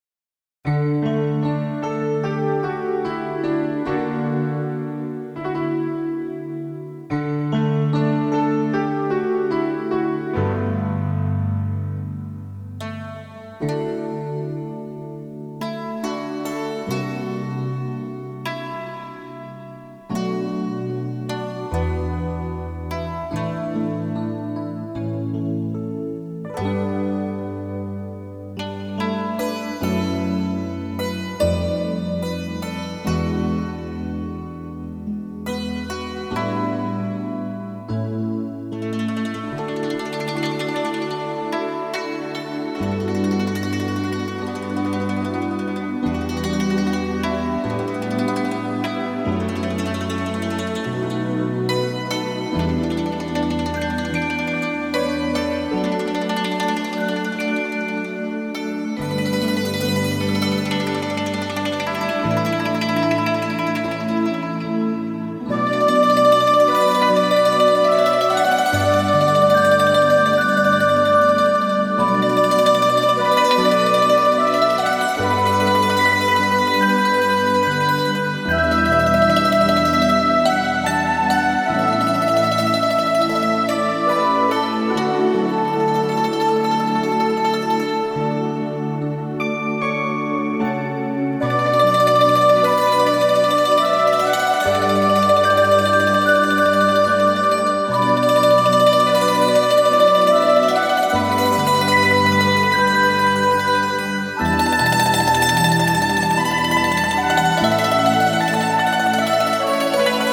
★ 融會貫通東西方樂器、傳統與現代的跨時代完美樂章！
★ 輕柔紓緩的美麗樂音，兼具令人震撼感動的發燒音效！